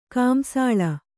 ♪ kāmsāḷa